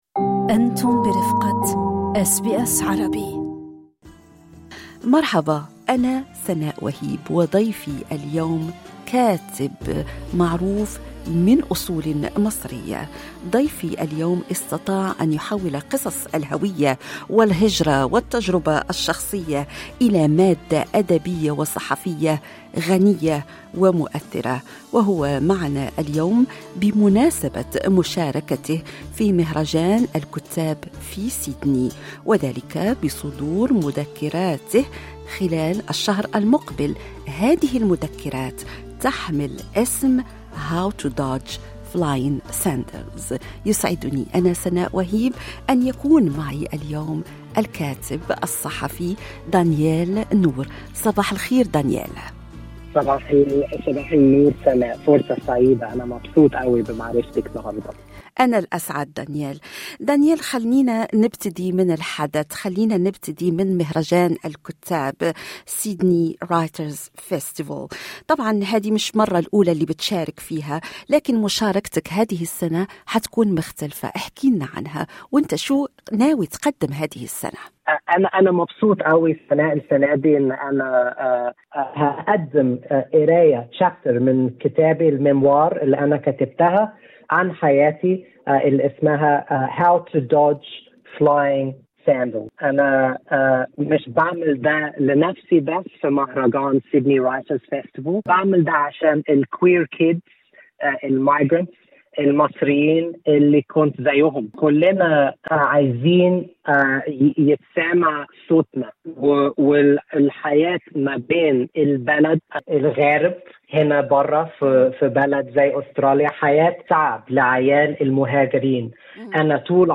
هذا ماسنعرفه في هذا اللقاء